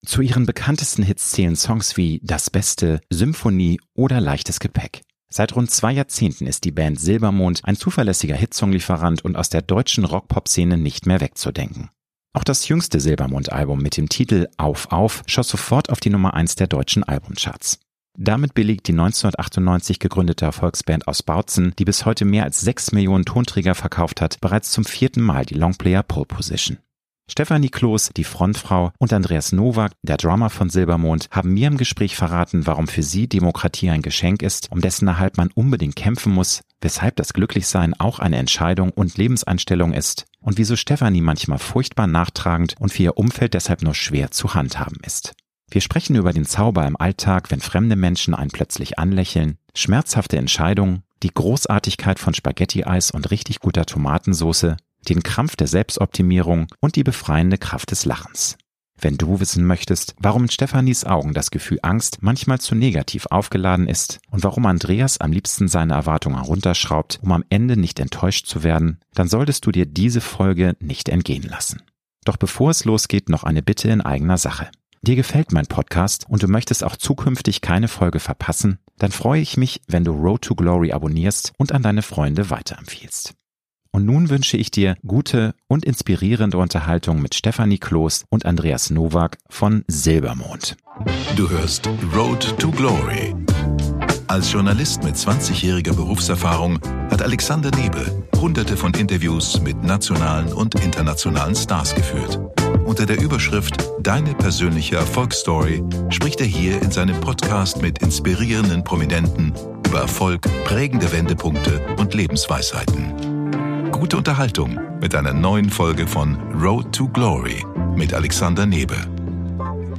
~ Road to Glory - Promi-Talk
Stefanie Kloß, die Frontfrau und Andreas Nowak, der Drummer von Silbermond, haben mir im Gespräch verraten, warum für sie Demokratie ein Geschenk ist, um dessen Erhalt man unbedingt kämpfen muss; weshalb das glücklich sein auch eine Entscheidung und Lebenseinstellung ist und wieso Stefanie manchmal furchtbar nachtragend und für ihr Umfeld deshalb nur schwer zu handhaben ist. Wir sprechen über den Zauber im Alltag, wenn fremde Menschen einen plötzlich anlächeln, schmerzhafte Entscheidungen, die Großartigkeit von Spaghetti-Eis und richtig guter Tomatensauce, den Krampf der Selbstoptimierung und die befreiende Kraft des Lachens.